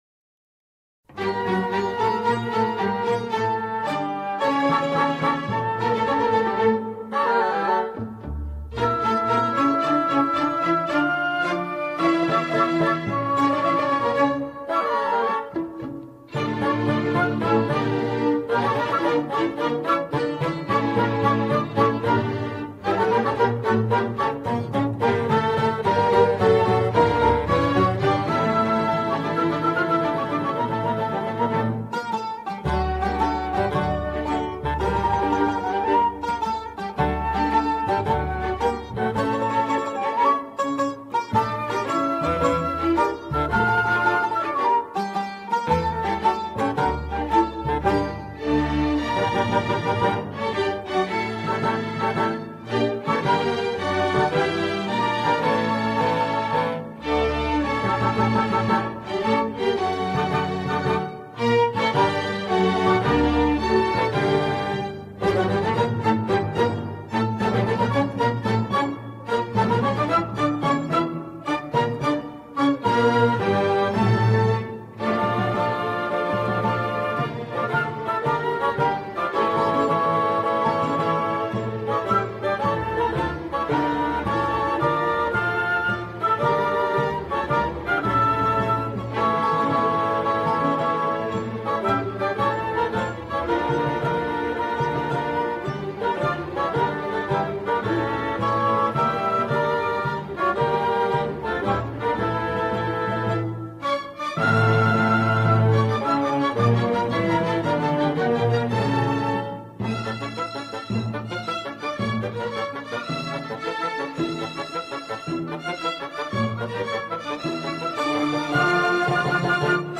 بی‌کلام